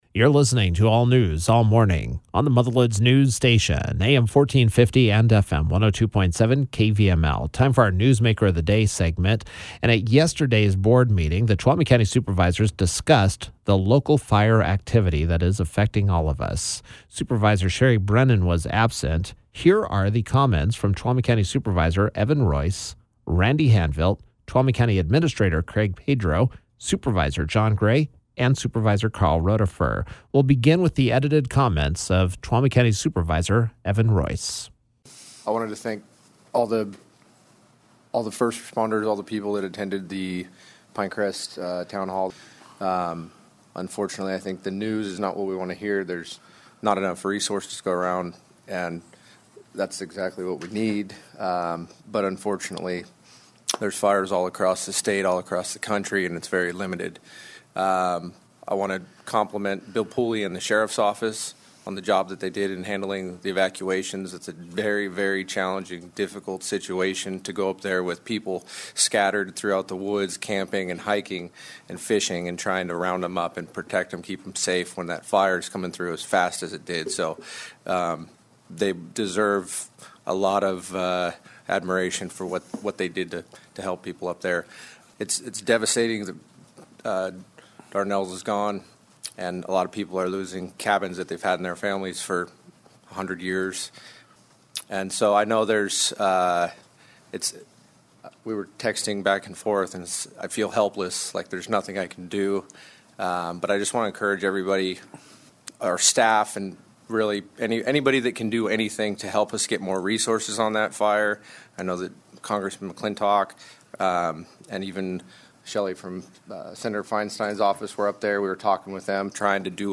Tuolumne County Supervisors Evan Royce, Randy Hanvelt, Karl Rodefer, John Gray and Tuolumne County Administrator Craig Pedro were all Wednesday’s KVML “Newsmakers of the Day”.